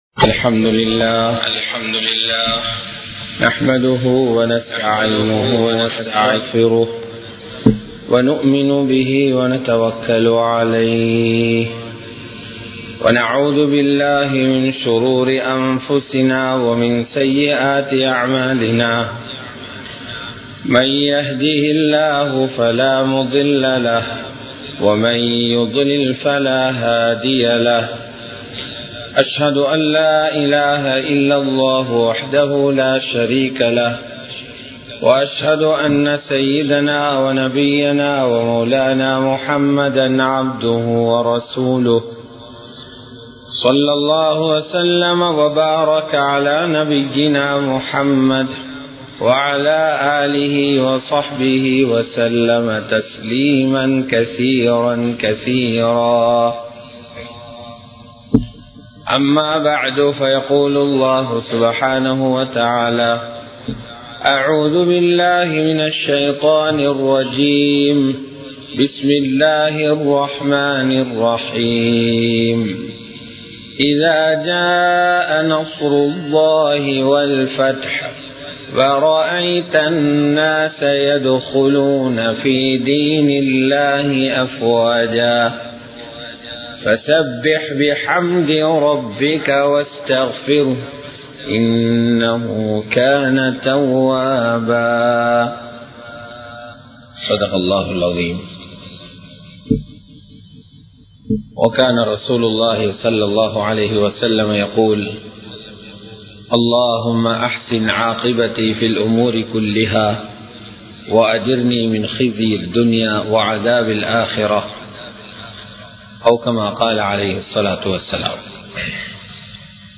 நபி(ஸல்)அவர்களின் இறுதி நாட்கள் | Audio Bayans | All Ceylon Muslim Youth Community | Addalaichenai
Colombo 03, Kollupitty Jumua Masjith